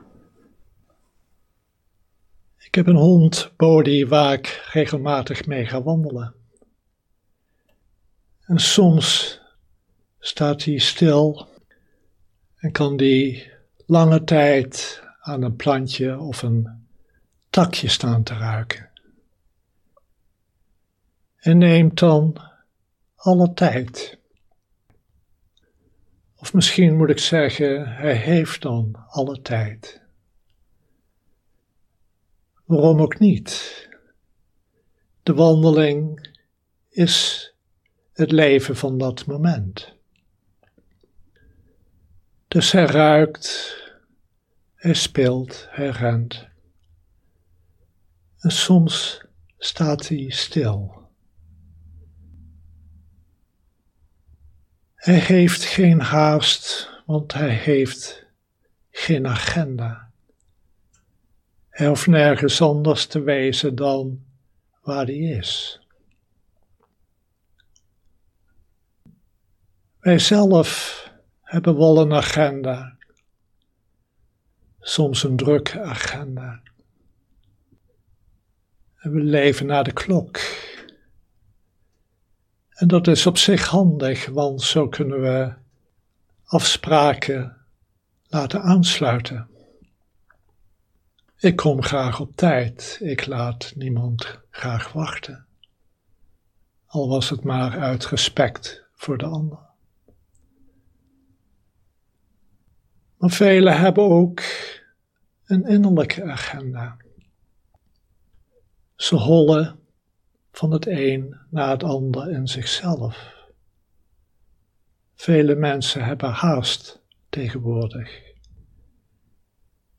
Livestream opname